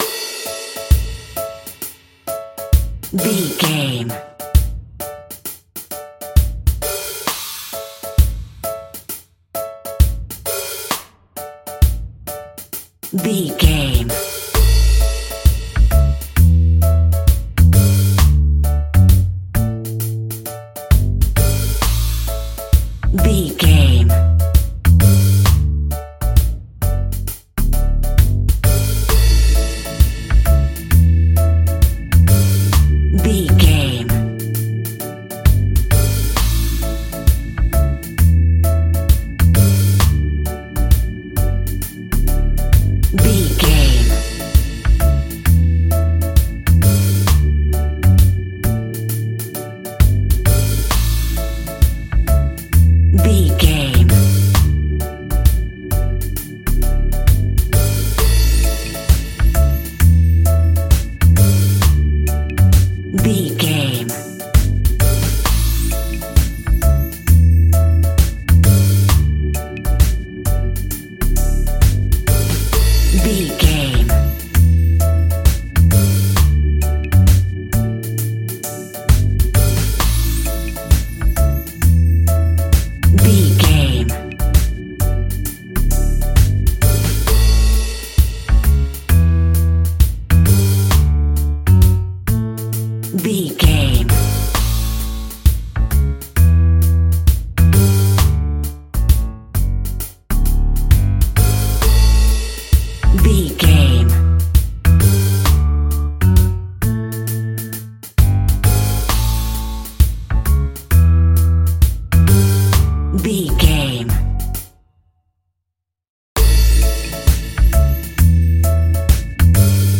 Exotic and world music!
Uplifting
Ionian/Major
Caribbean
jamaican
strings
brass
percussion